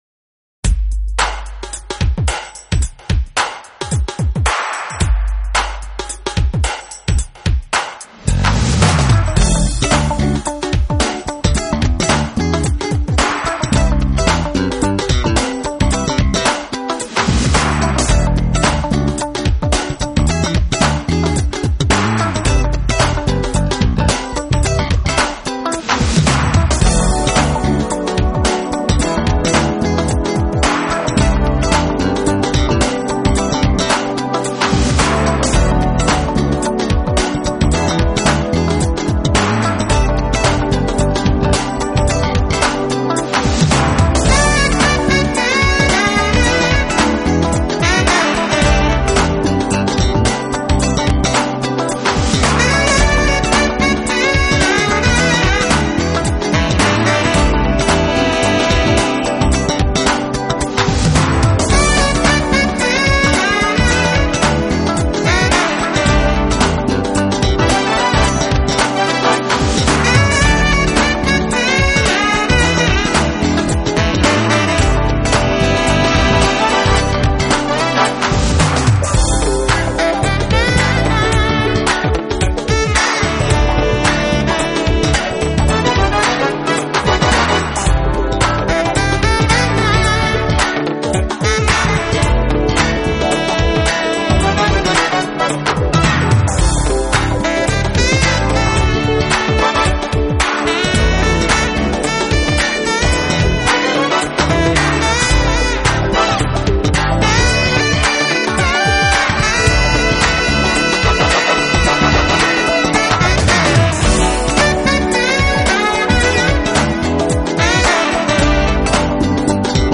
Genre: Jazz
乐 曲多半带有浓浓的放客节奏和迷人性感的旋律